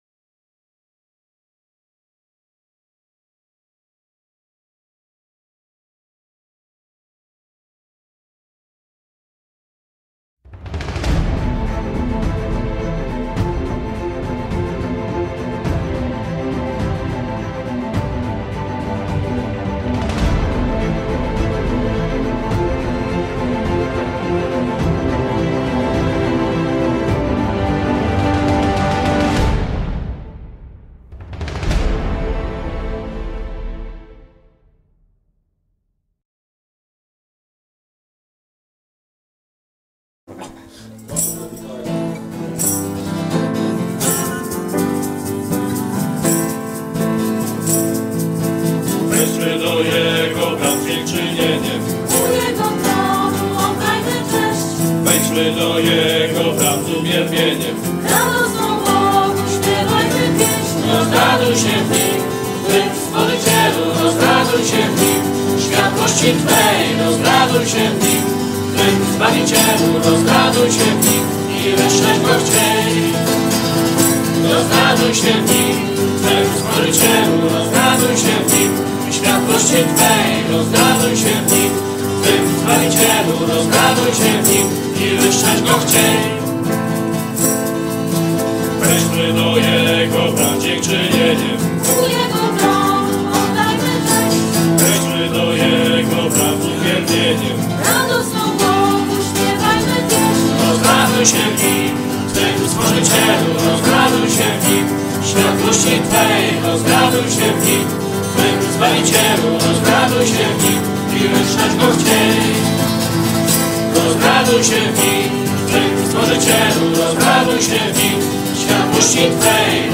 Nauczanie